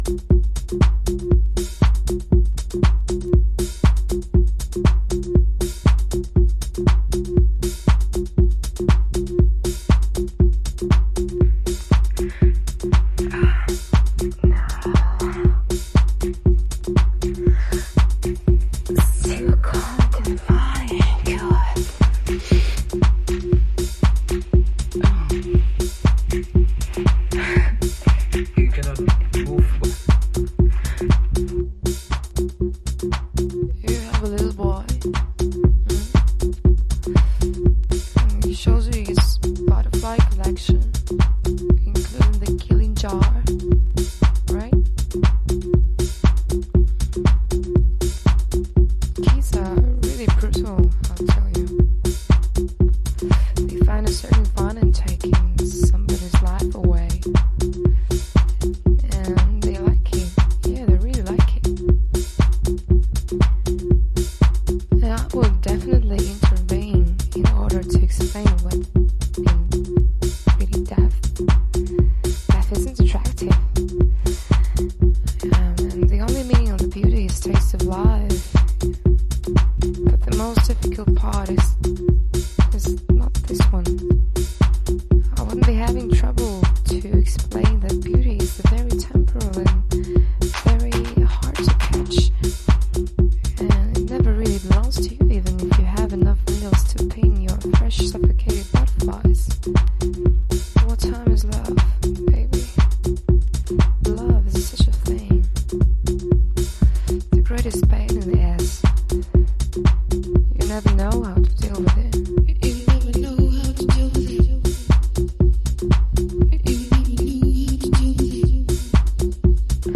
淡々と紡ぐビートにすけべえなウィスパー・ヴォイス
後半にトリッピーなシンセが差し込まれる